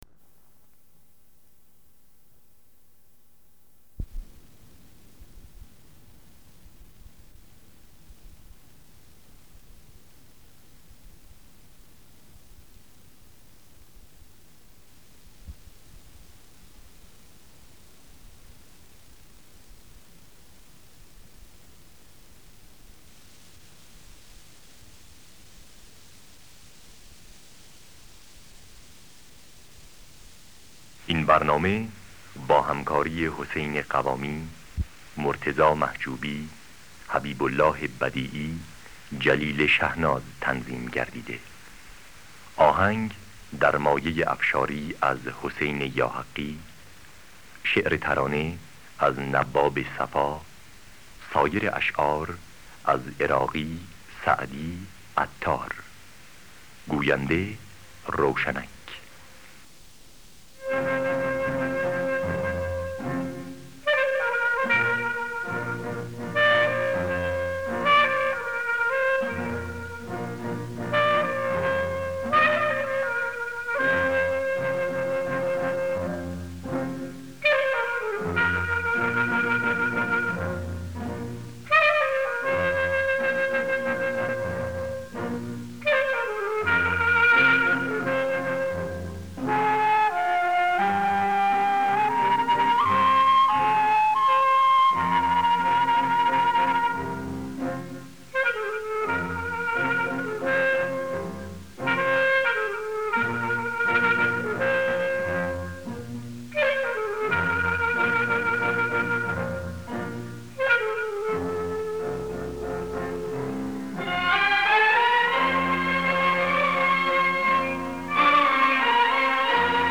دانلود گلهای رنگارنگ ۲۴۰ با صدای حسین قوامی در دستگاه افشاری.